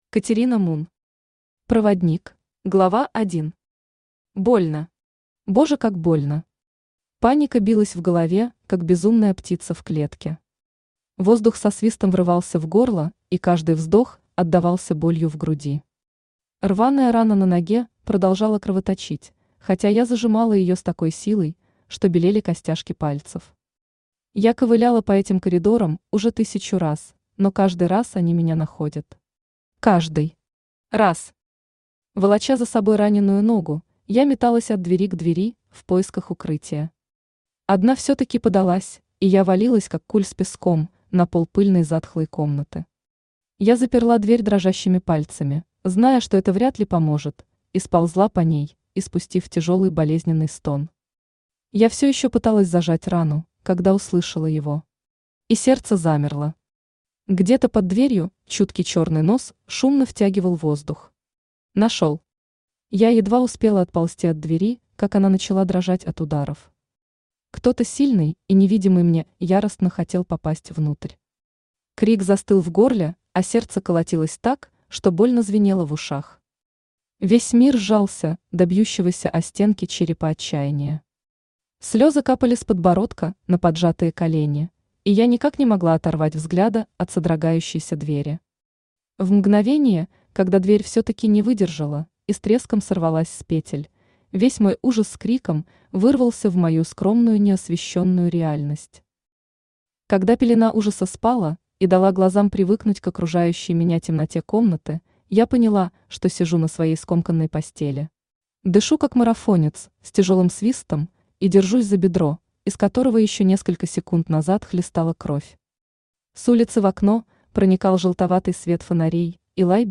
Аудиокнига Проводник | Библиотека аудиокниг
Aудиокнига Проводник Автор Катерина Мун Читает аудиокнигу Авточтец ЛитРес.